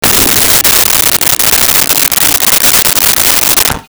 Toilet Flush
Toilet Flush.wav